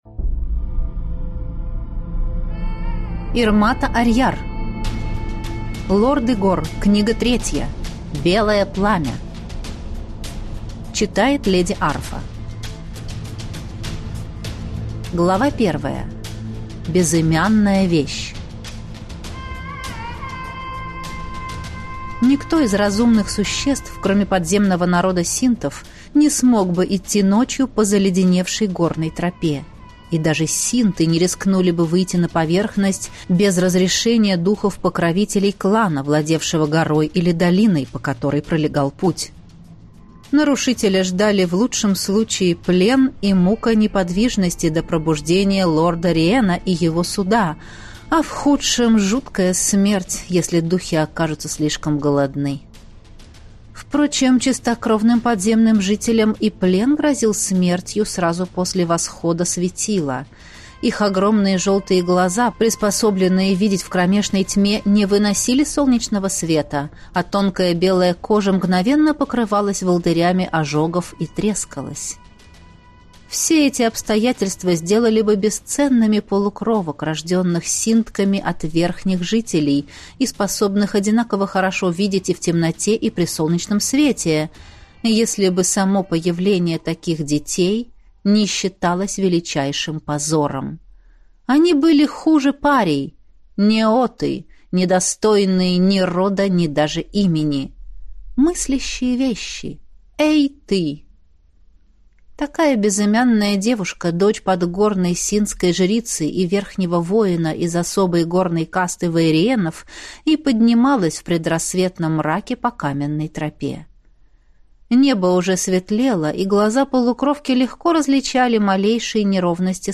Аудиокнига Лорды гор. Книга 3. Белое пламя | Библиотека аудиокниг